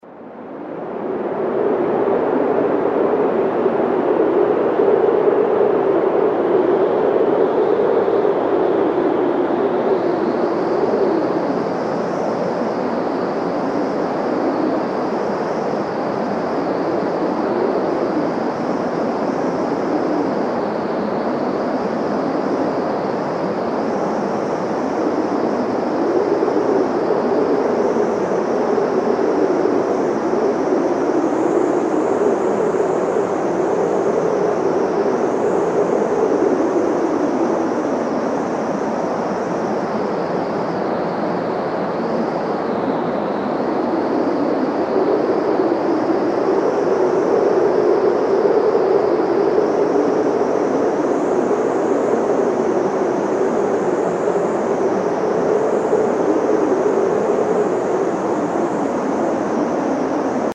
Звуки вьюги, метели
8. Мощная метель